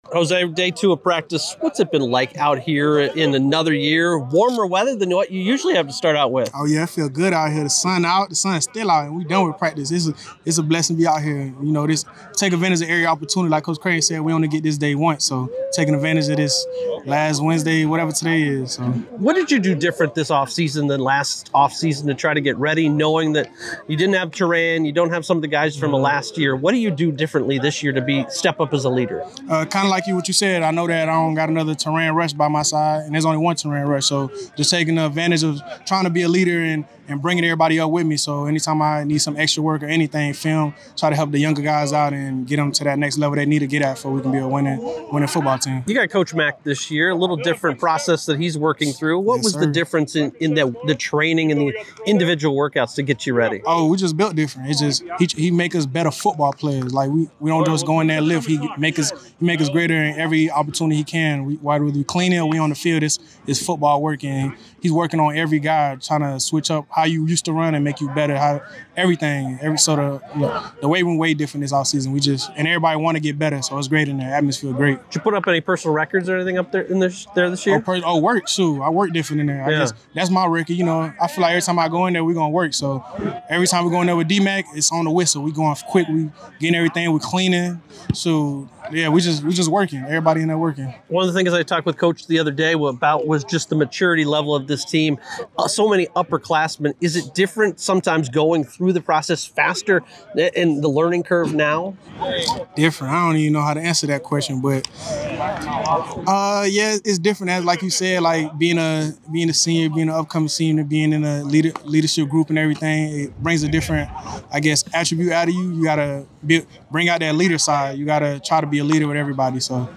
From the Field - Day Two: Football Spring Practice Interview Series